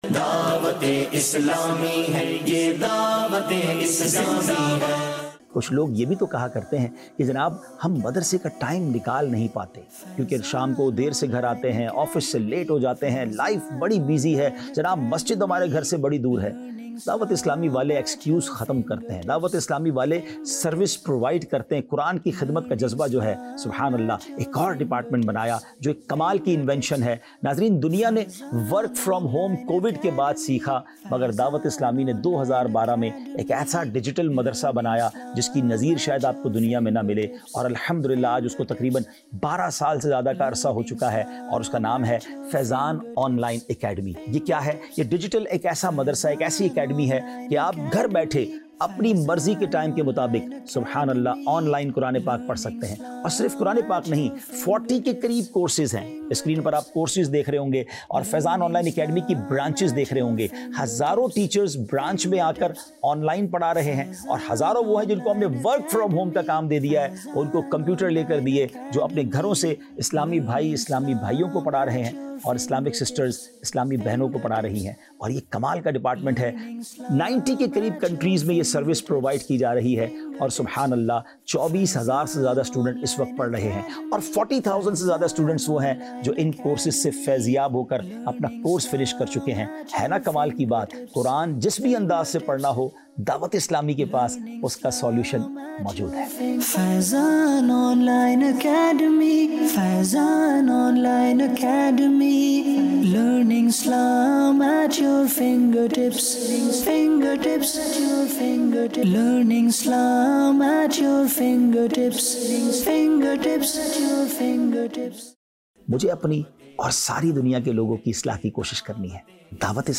Faizan Online Academy | Department of Dawateislami | Documentary 2025